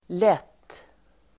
Uttal: [let:]